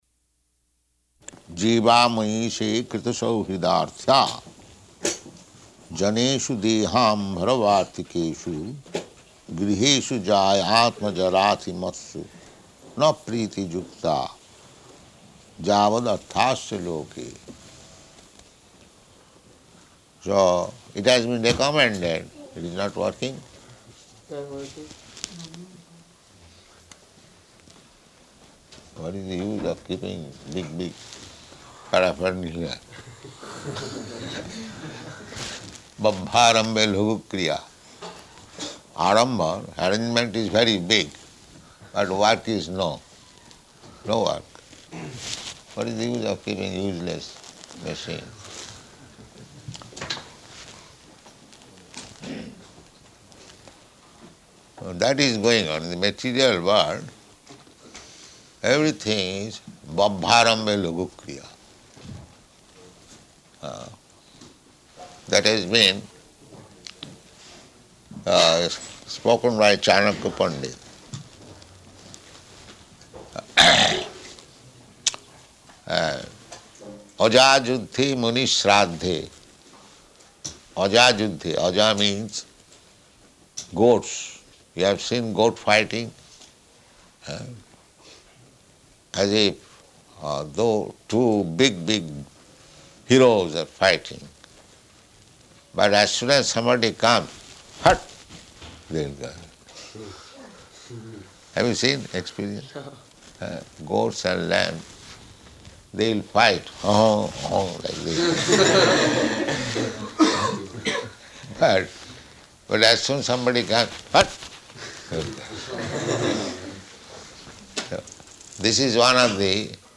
Location: Stockholm